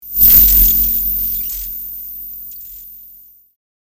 Electrifying Texture, Electric Shock 3 Sound Effect Download | Gfx Sounds
Electrifying-texture-electric-shock-3.mp3